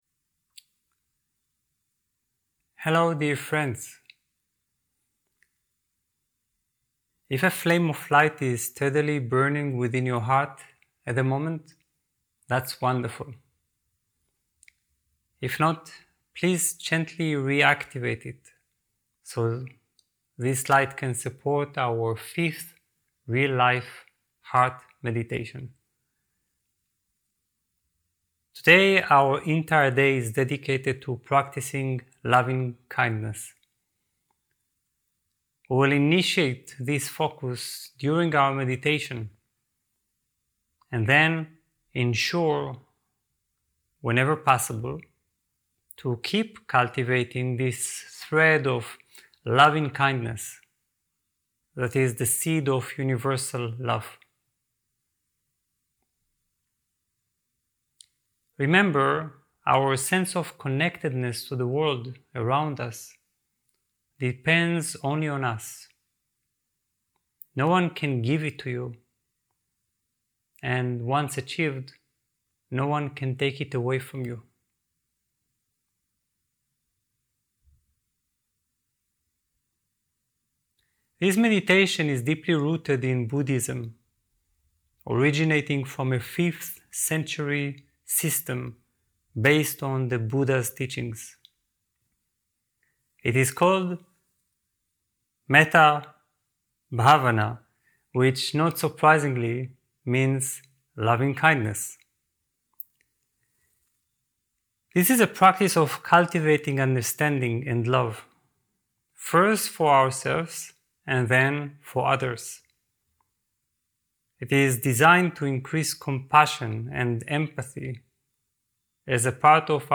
Meditation auf deutsch